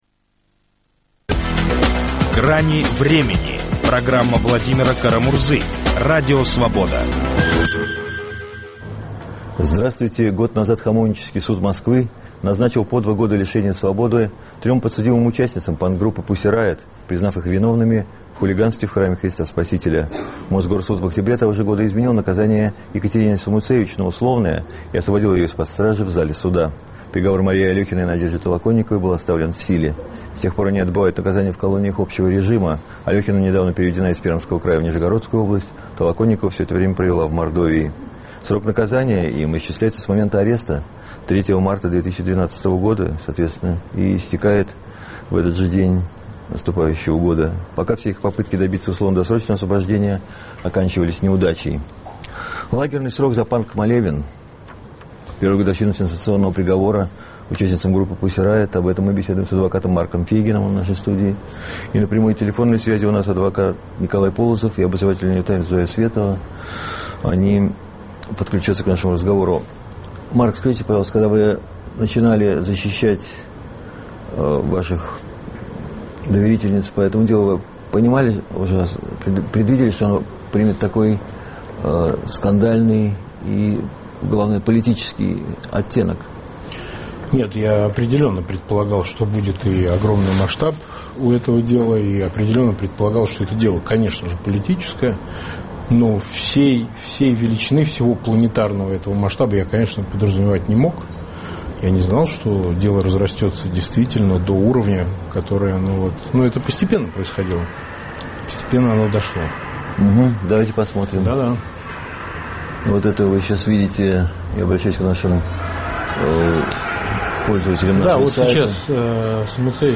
беседуем с адвокатами